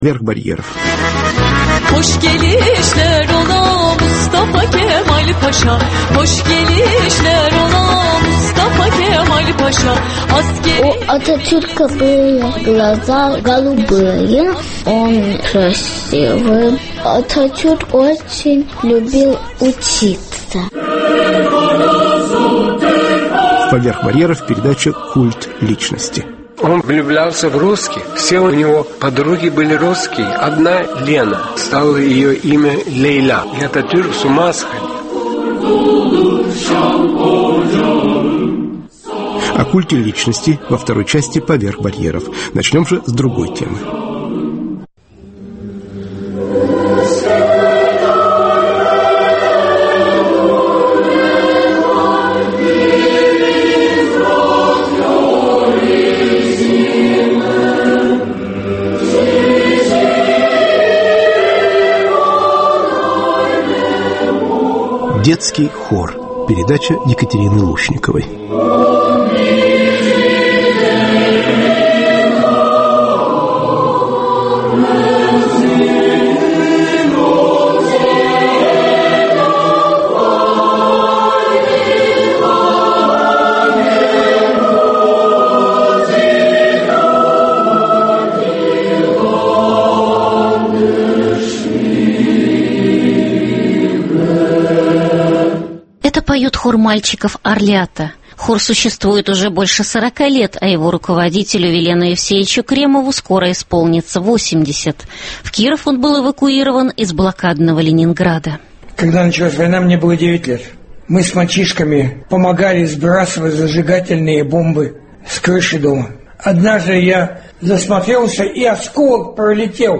Дети и взрослые музыканты - о музыке, любви, ломке голоса.